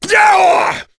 Kaulah-Vox_Attack6.wav